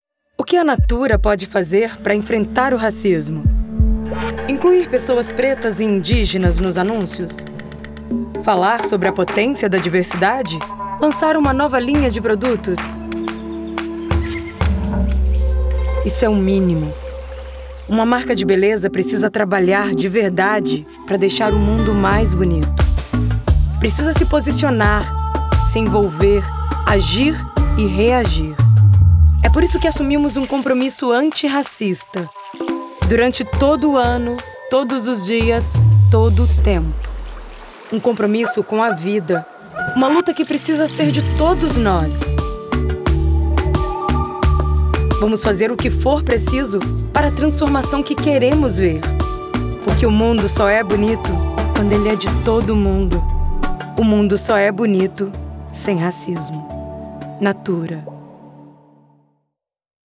Voz com um leve “rouquinho” pra deixar seu anuncio com um toque único, trazendo mais sofisticação e visibilidade no mercado publicitário.